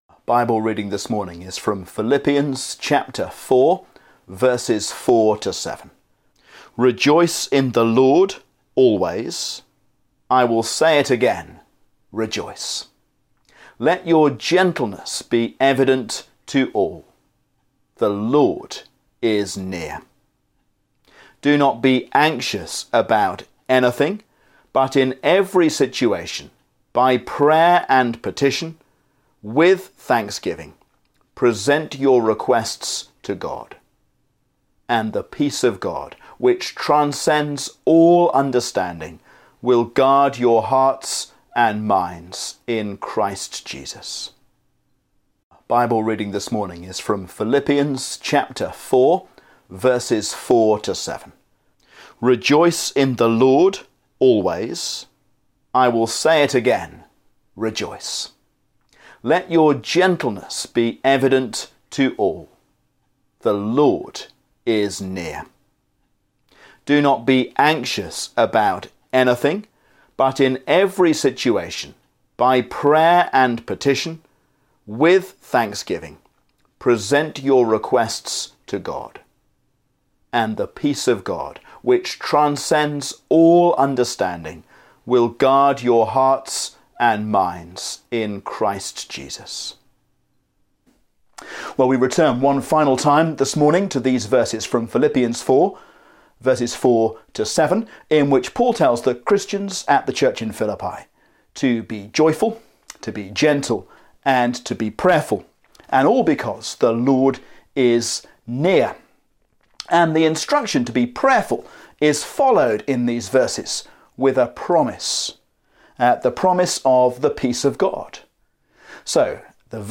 The Peace of God, A sermon on Philippians 4:4-7